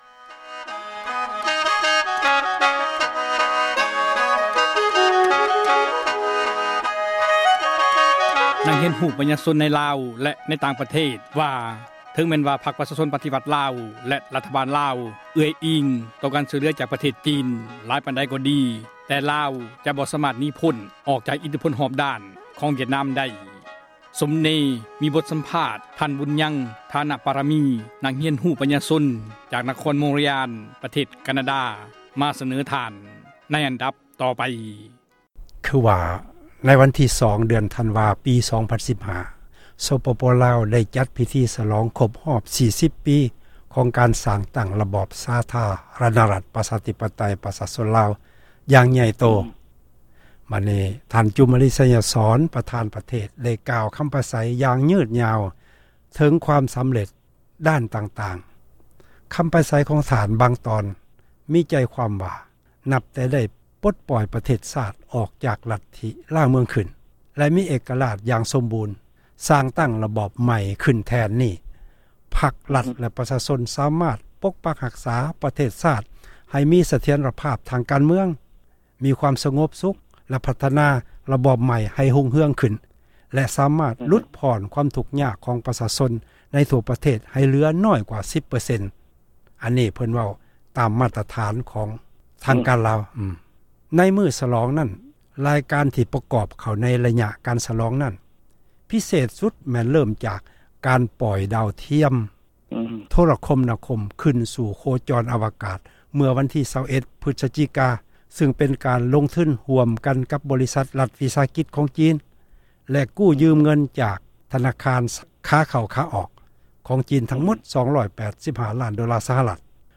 ເຊີນທ່ານ ຕິດຕາມ ຟັງ ການສໍາພາດ ຕໍ່ໄປໄດ້.